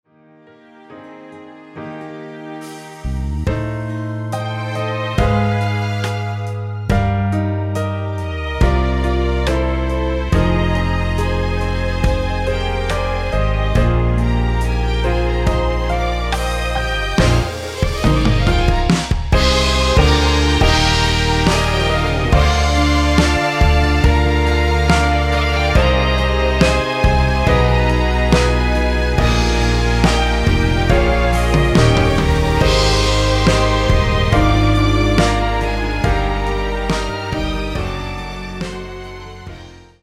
원키에서(+5)올린(1절앞 + 후렴)으로 진행되는 MR입니다.
Eb
앞부분30초, 뒷부분30초씩 편집해서 올려 드리고 있습니다.